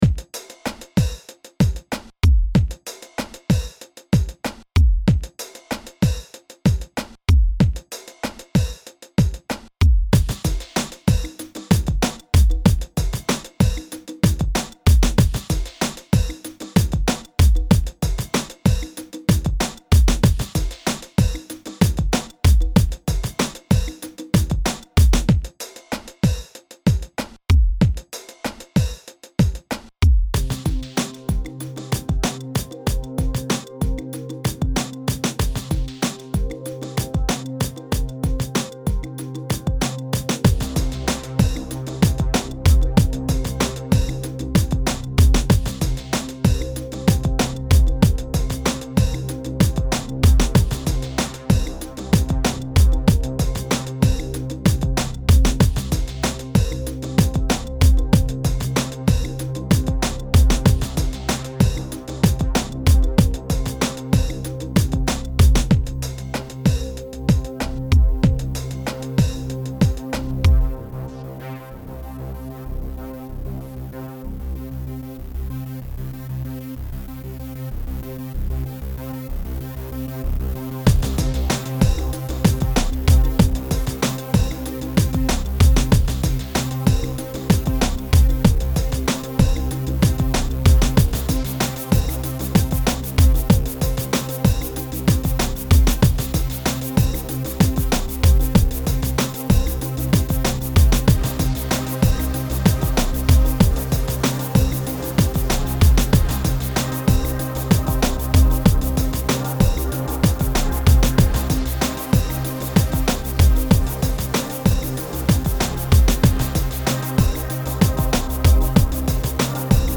Enregistré et mixé à LGS Studio 1, BBA,